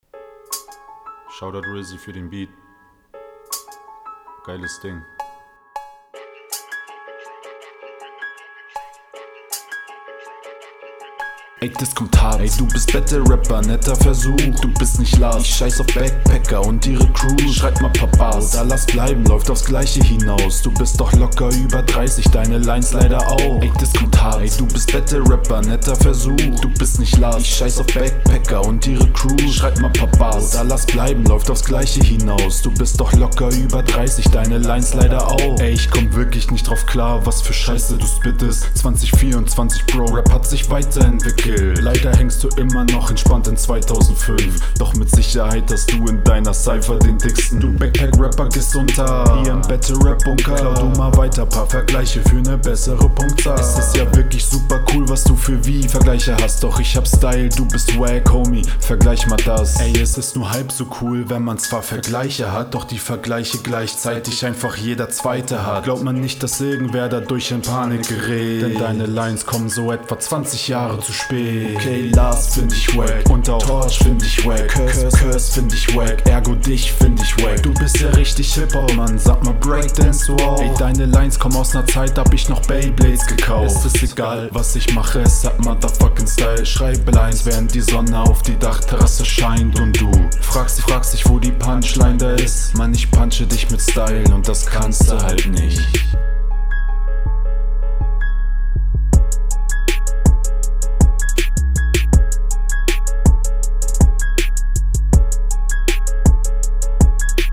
Schöner Beat und du steigst da smooth ein, was mir soweit gut gefällt, da die …
Doubles sehr unsauber, könntest mit bisschen mehr Druck rappen